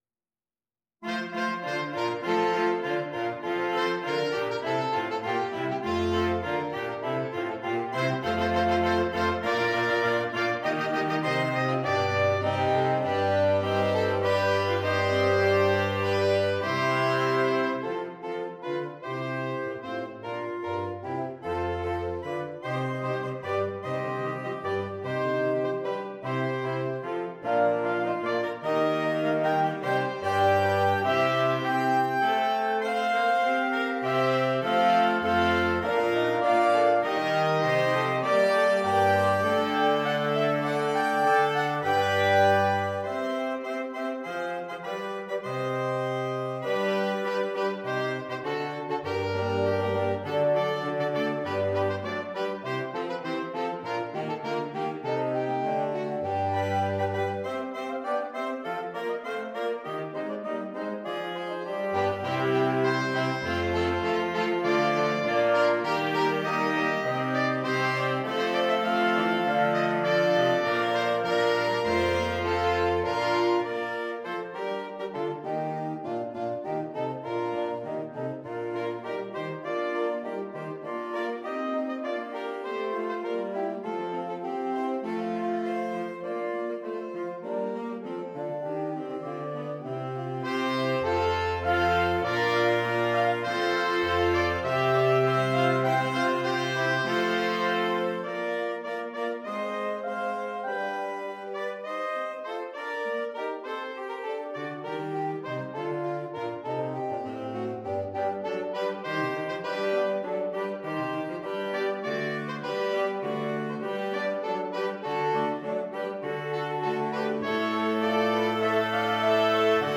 Saxophone Ensemble